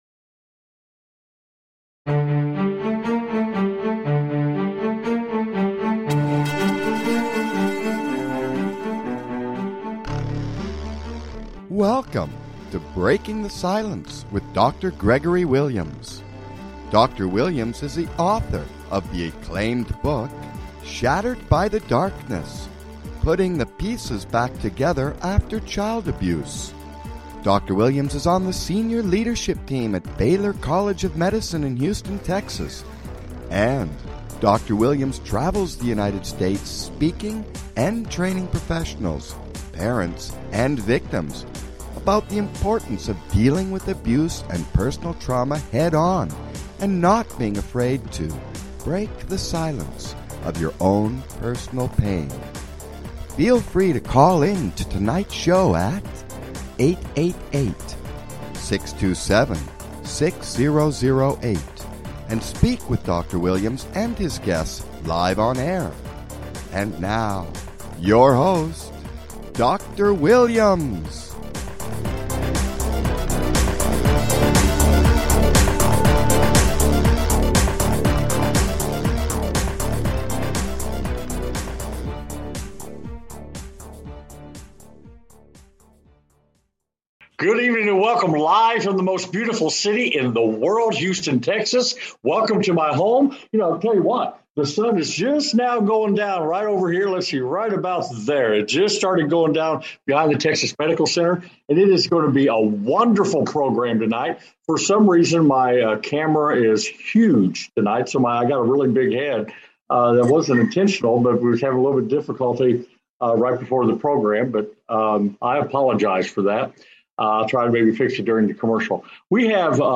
Breaking the Silence Talk Show